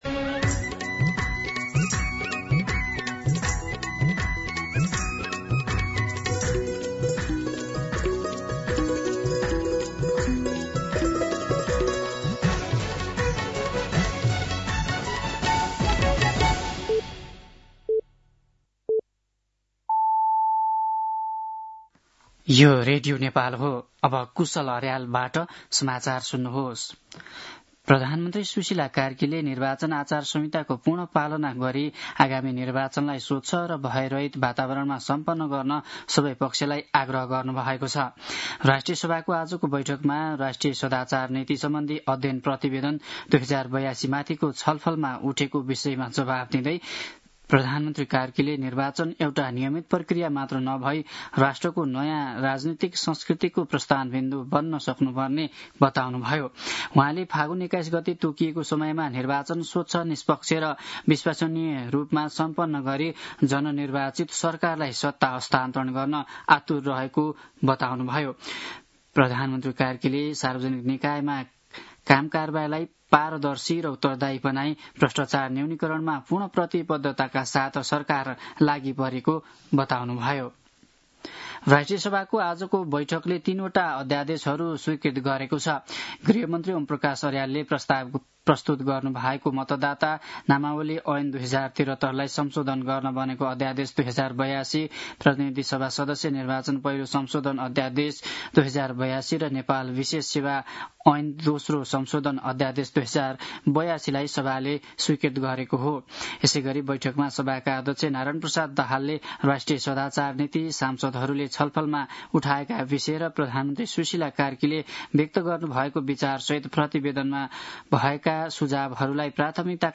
दिउँसो ४ बजेको नेपाली समाचार : १९ माघ , २०८२